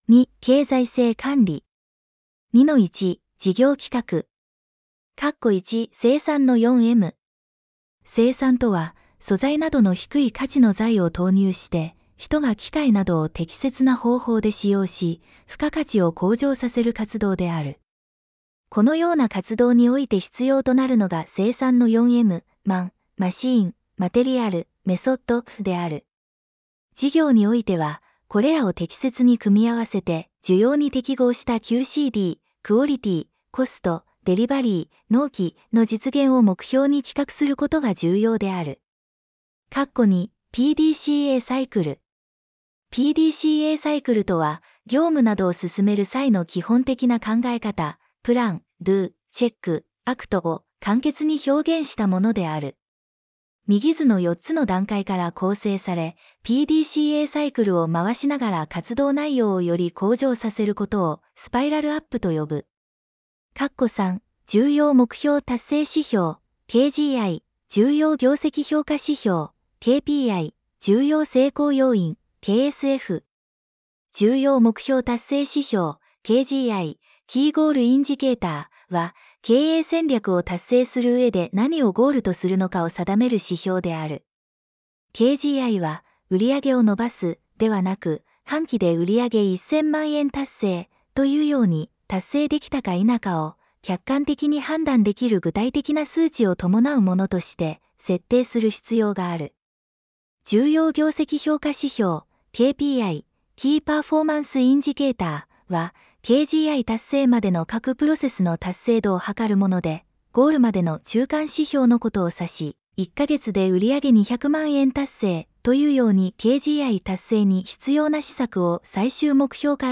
テキストサンプル 音声ファイルサンプル いますぐダウンロード特別価格で購入する ※音声ファイルは聞きやすさに配慮しAI音声を採用しています。そのため、一部に発音の違和感を覚える箇所がありますが、その分、記憶には残りやすくなっています。